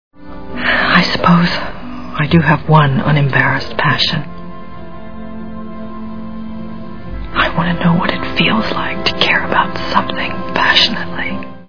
Adaptation Movie Sound Bites